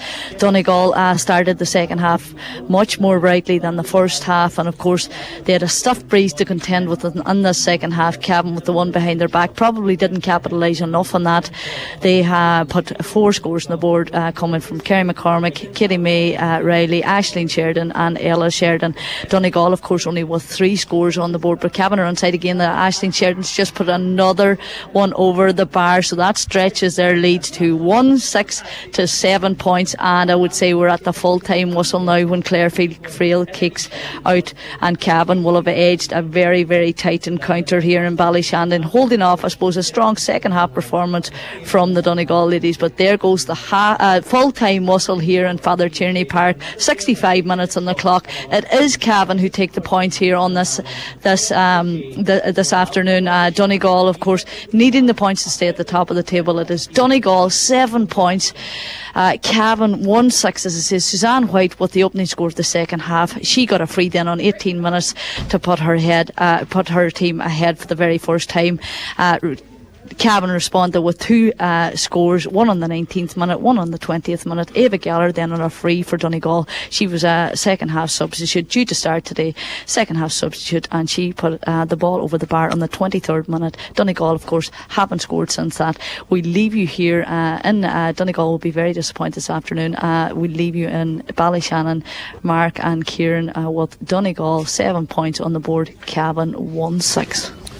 was live as full time approached for Highland Radio Sport…